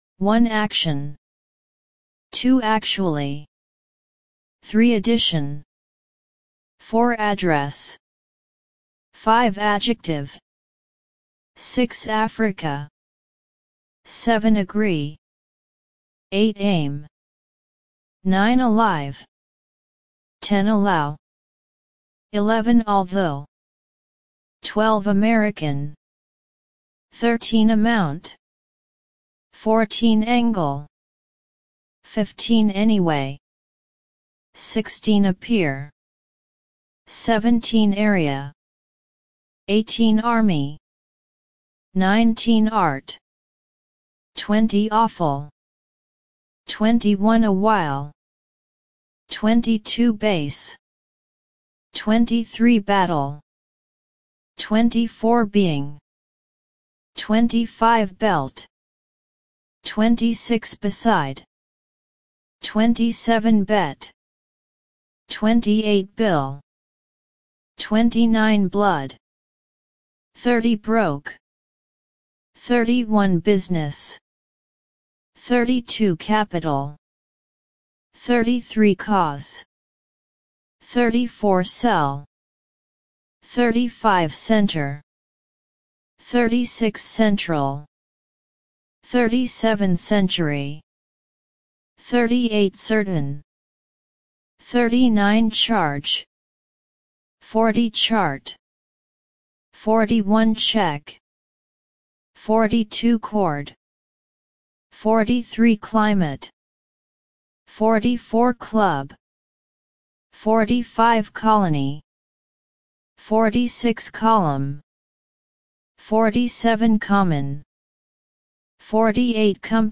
1 – 50 Listen and Repeat